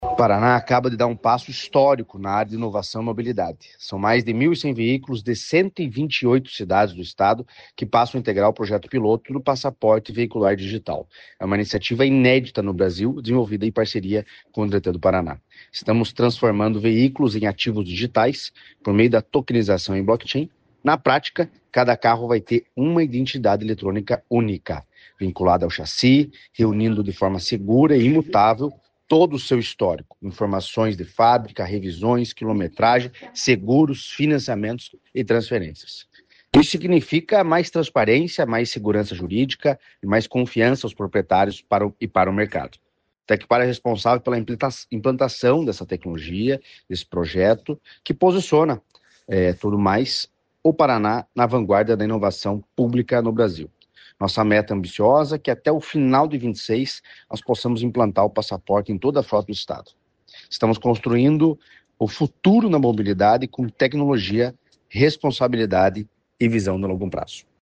Sonora do diretor-presidente do Tecpar, Eduardo Marafon, sobre o projeto-piloto do Passaporte Veicular Digital